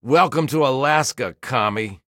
Fo3OA_AmericanSoldier_WelcomeToAlaska.ogg